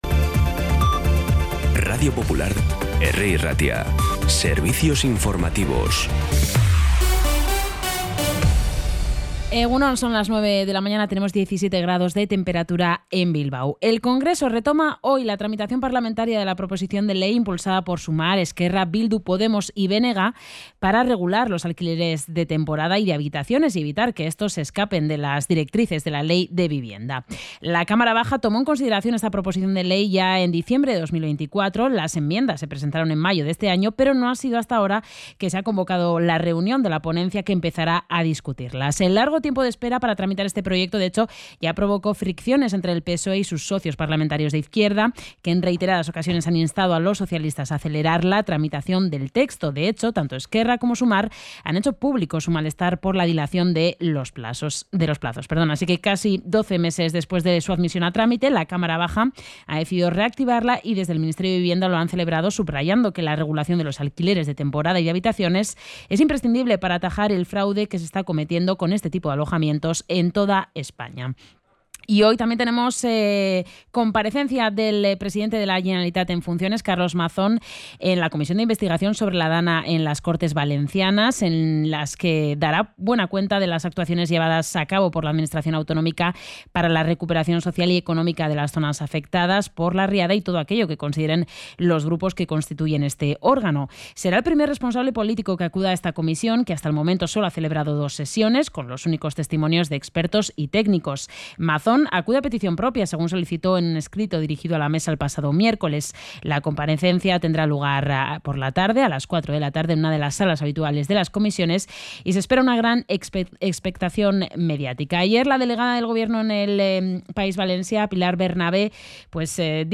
Las noticias de Bilbao y Bizkaia de las 9 , hoy 11 de noviembre
Los titulares actualizados con las voces del día. Bilbao, Bizkaia, comarcas, política, sociedad, cultura, sucesos, información de servicio público.